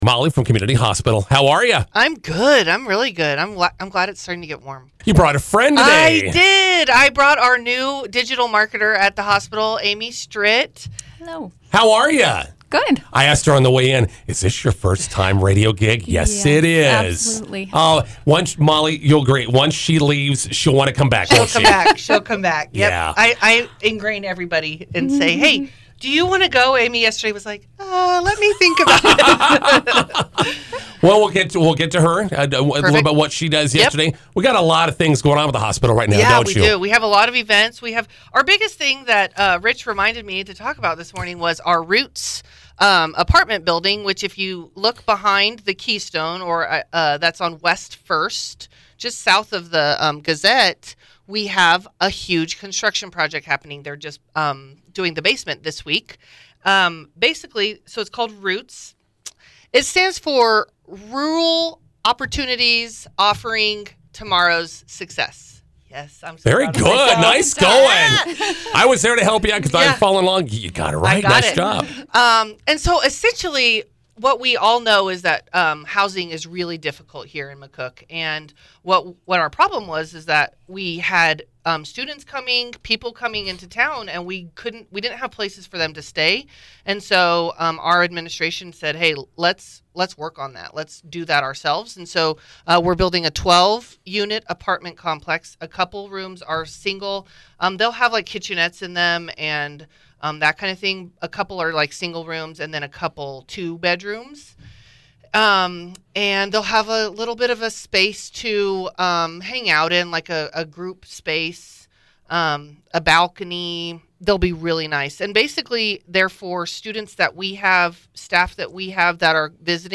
INTERVIEW: Community Hospital Roots Student Housing project continues.